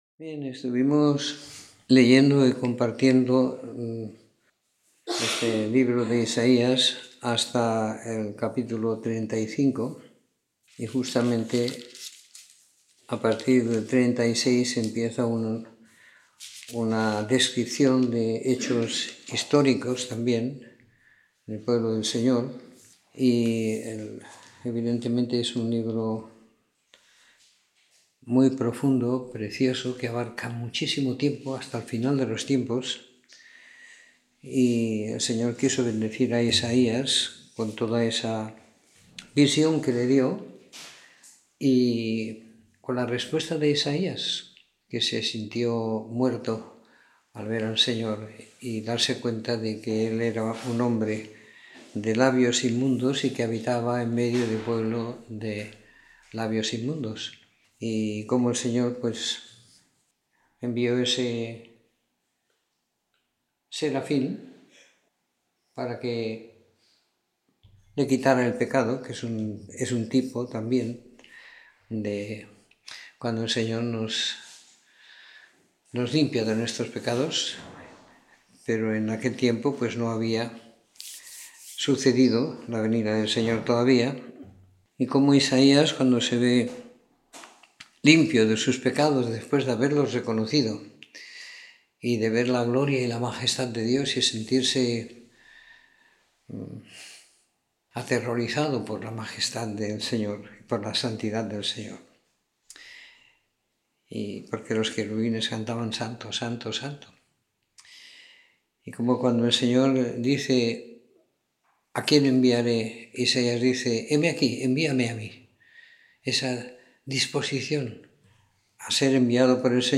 Comentario en el libro de Isaías siguiendo la lectura programada para cada semana del año que tenemos en la congregación en Sant Pere de Ribes.